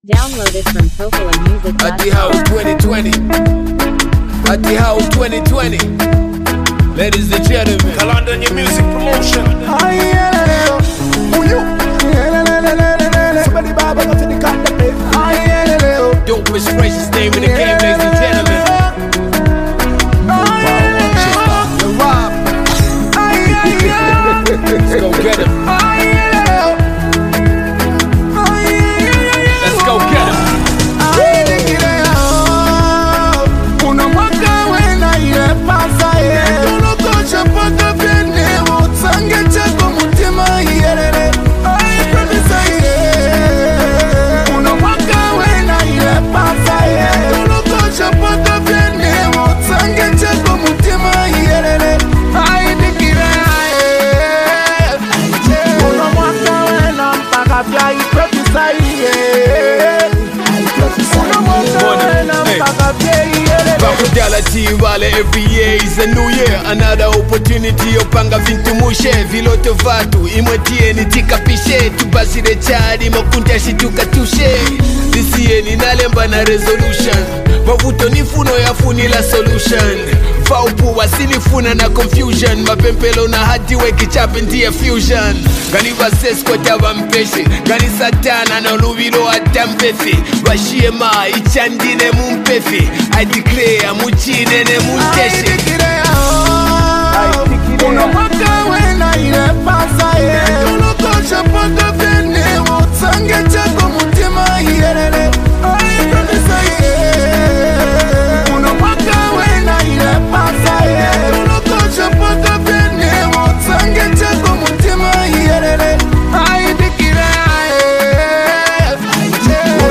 a balanced blend of rap and melody